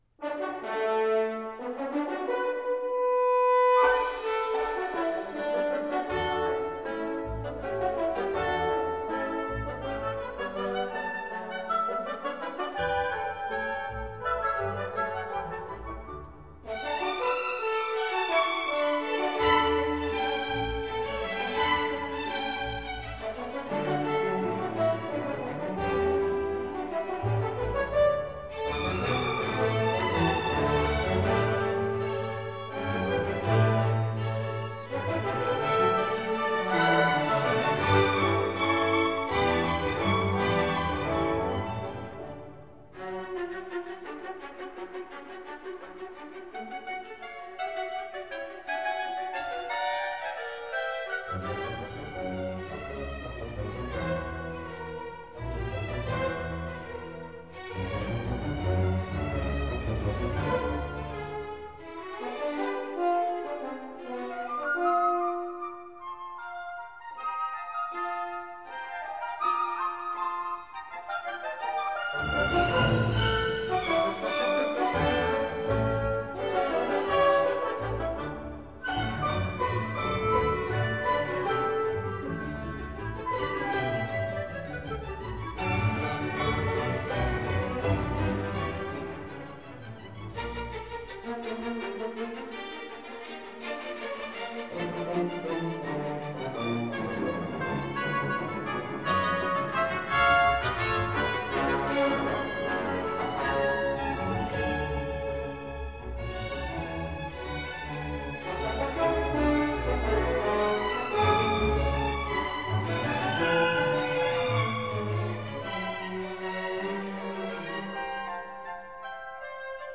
Scherzo.